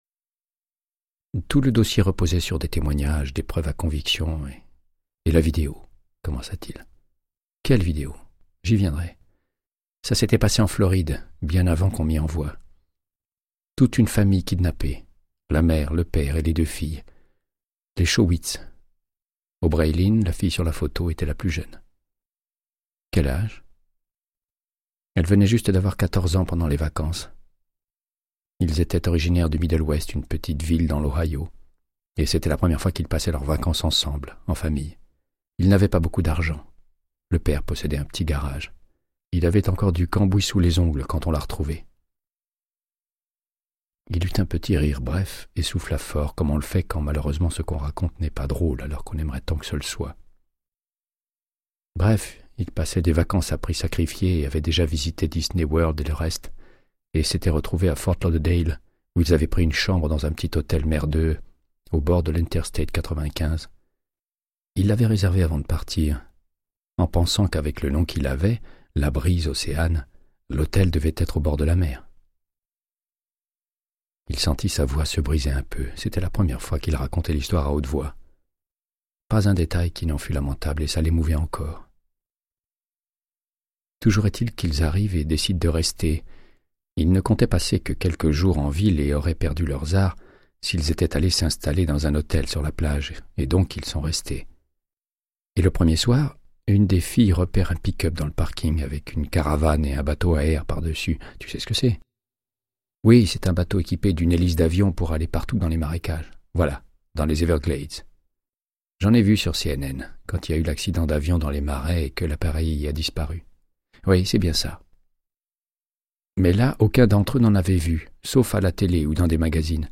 Audiobook = Créance de sang, de Michael Connellly - 94